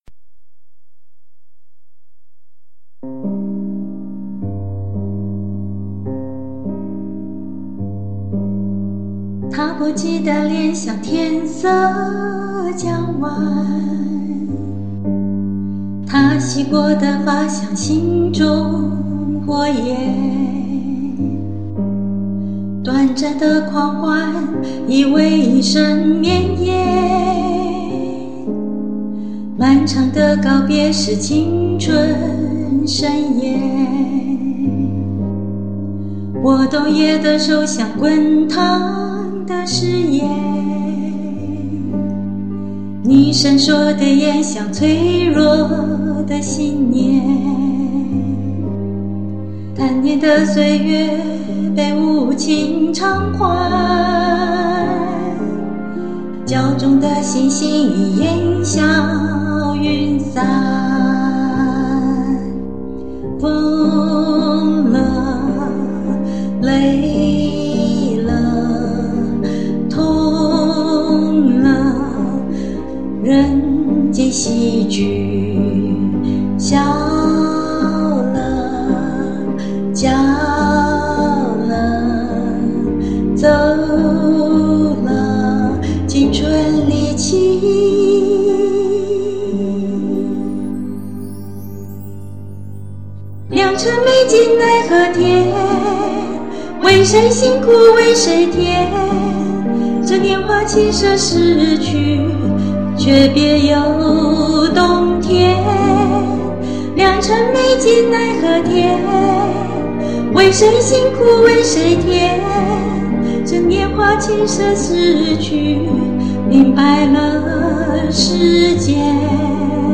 錄音設備需要更新， Samson USB condenser microphone 有不少選擇，amazon上。
唱得很深情，謝謝分享。